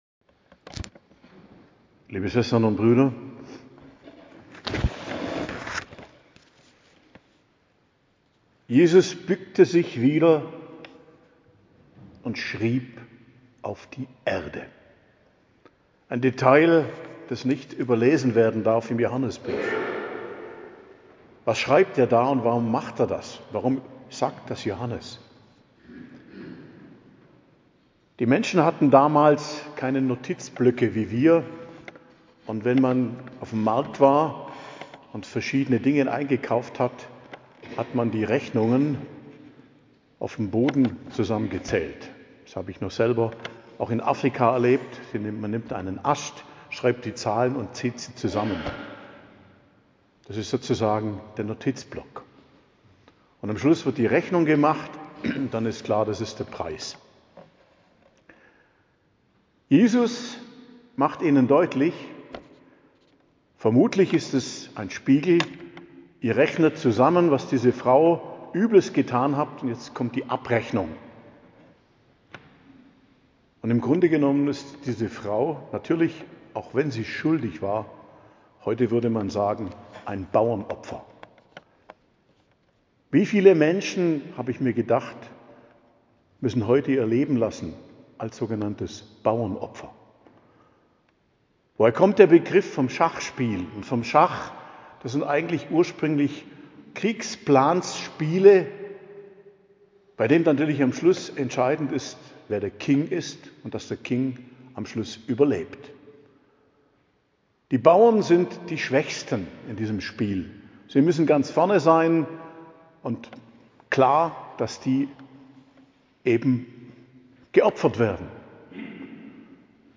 Predigt zum 5. Fastensonntag am 6.04.2025 ~ Geistliches Zentrum Kloster Heiligkreuztal Podcast